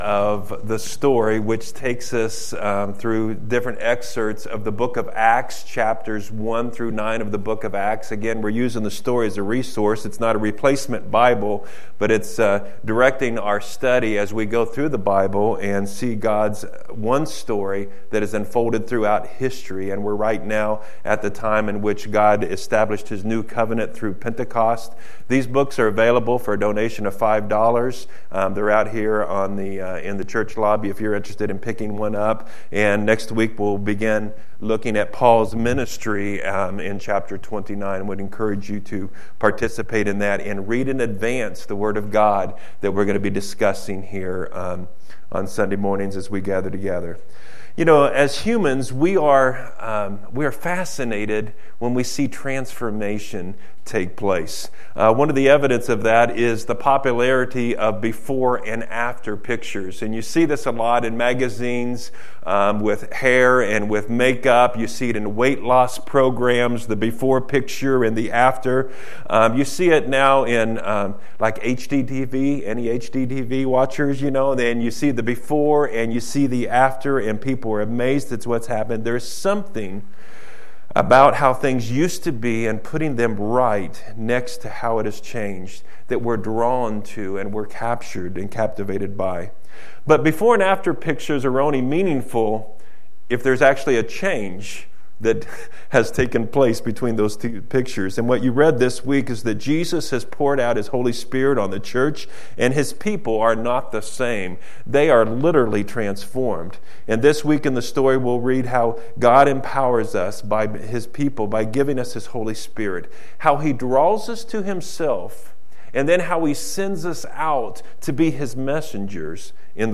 December 29 2013, no notes accompany this sermon